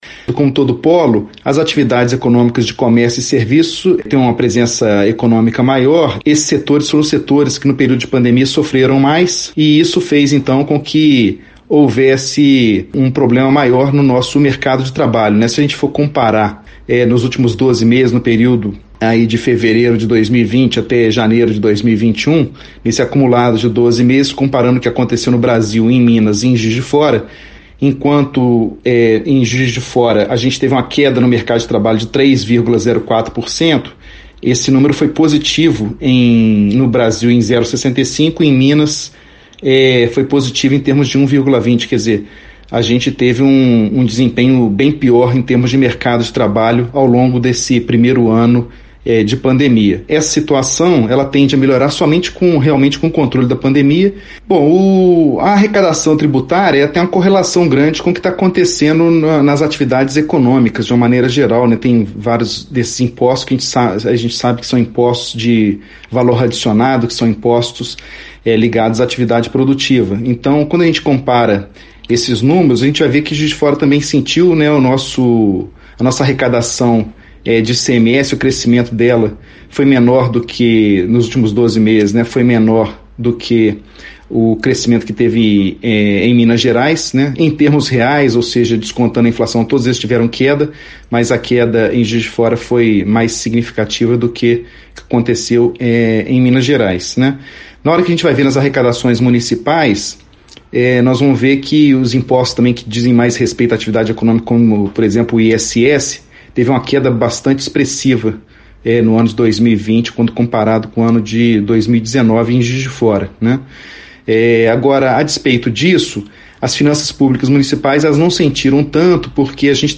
economista